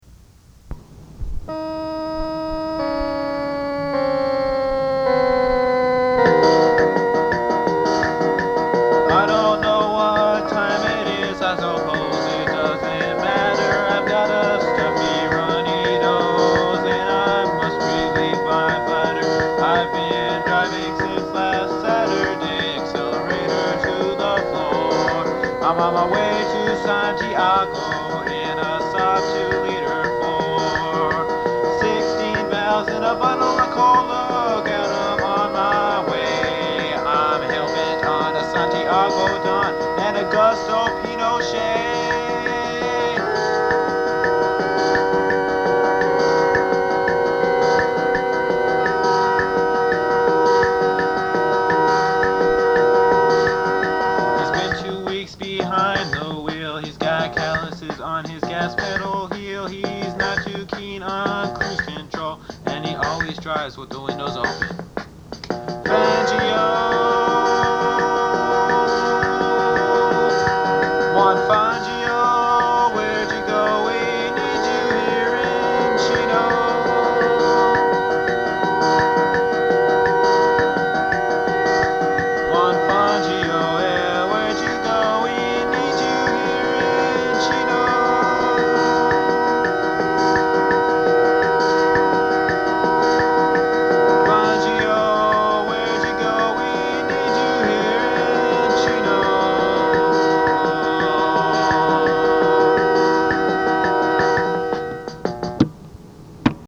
I wrote a song for my Casio-powered solo project, Party of One, in which I imagined the retired legend Juan Manuel Fangio piloting a then-current model Saab Turbo across the Andes mountains on a covert mission to assassinate the Chilean dictator Augusto Pinochet.
The Casio has been replaced by GarageBand.